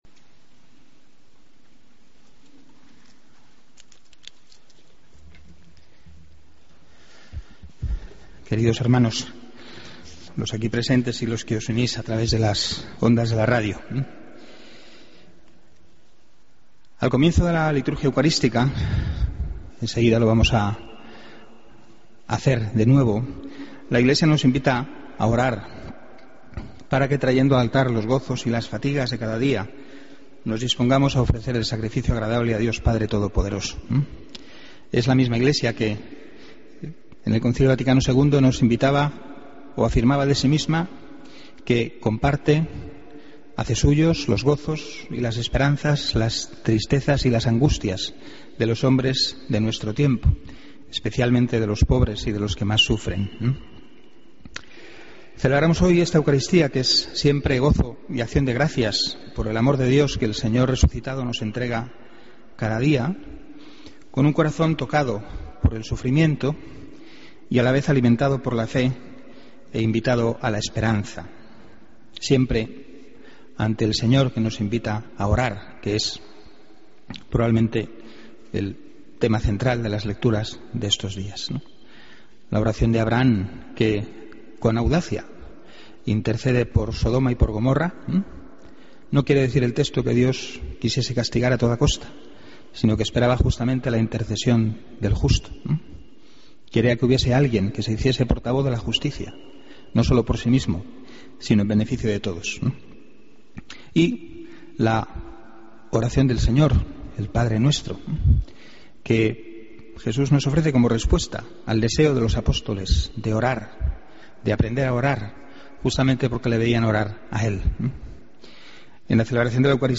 Homilía, domingo 28 de julio de 2013